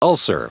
Prononciation du mot ulcer en anglais (fichier audio)
Prononciation du mot : ulcer